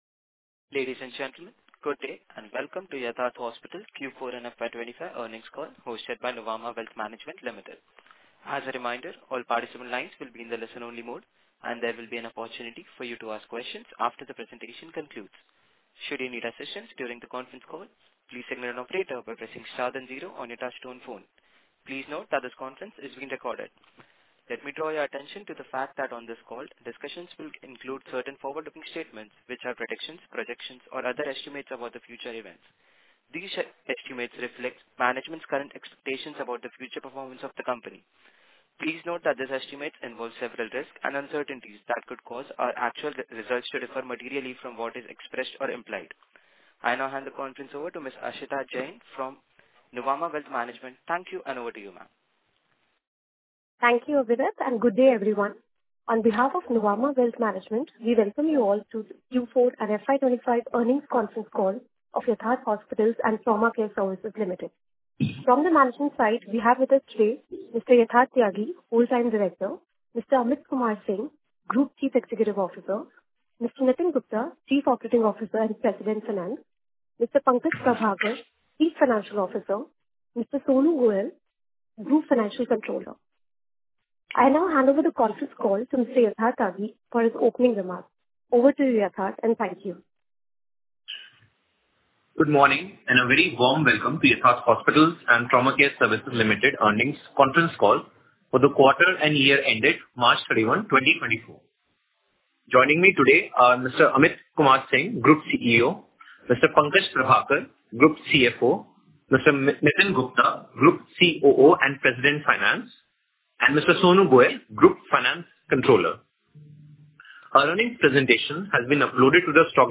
Earning_Call_Recording_for_Q4&FY25.mp3